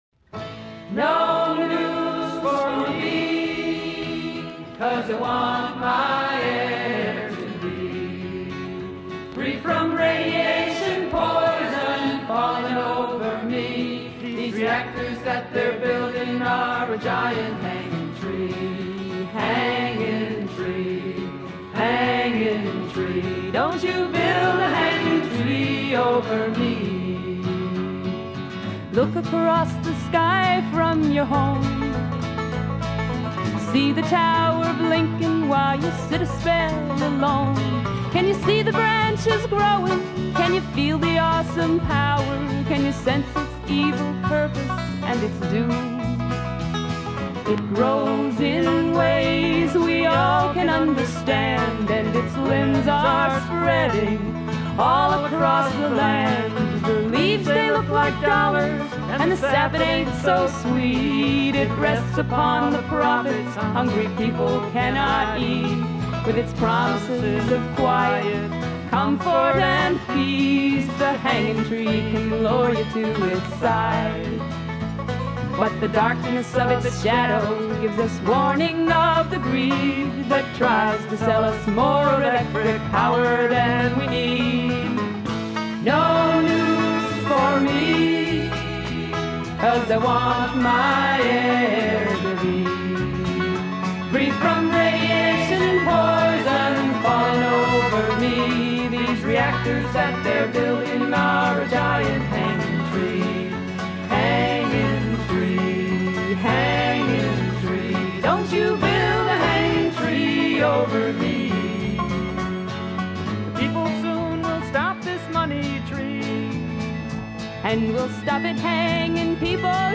Available are recordings -Songs, Interviews of scientists, farmers, activists and misc sounds at misc public demonstrations including Chernobyl, Ukraine & Fukushima, Japan.  Here is a unedited selection.